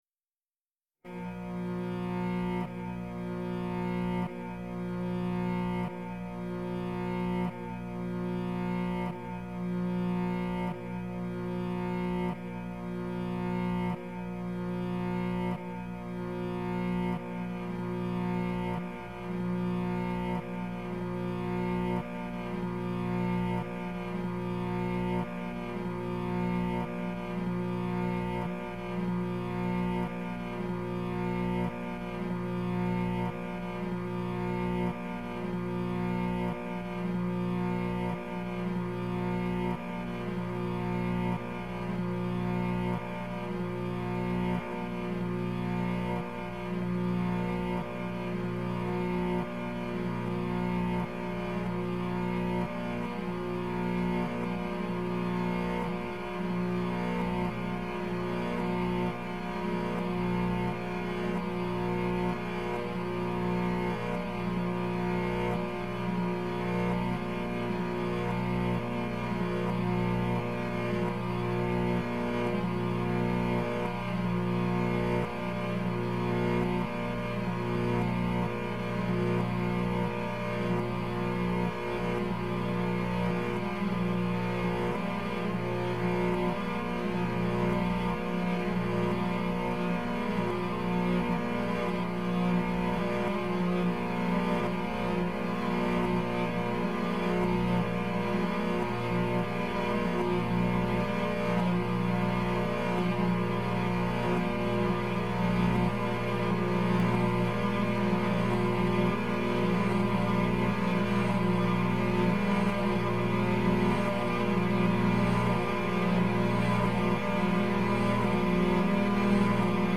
As semper: SING mY FOLLOWING ON background pulses:
Cellos Waving_5.mp3